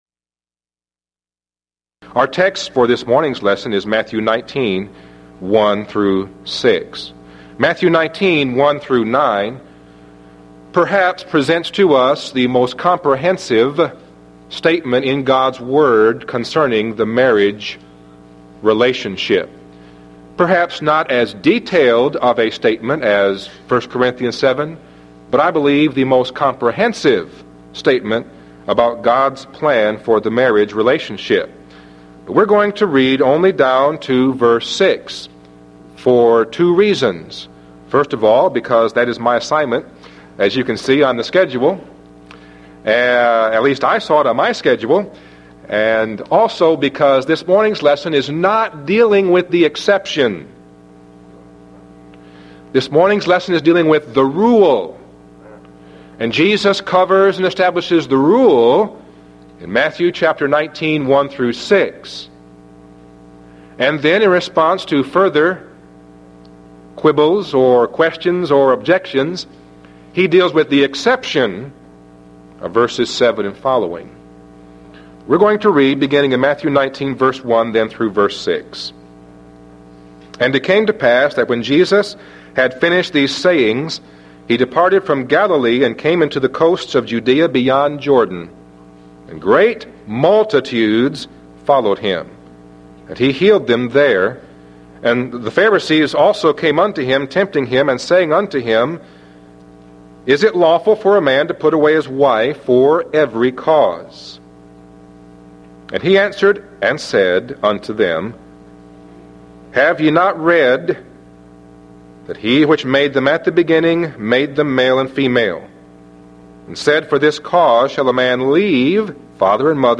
Event: 1993 Mid-West Lectures Theme/Title: The Christian Family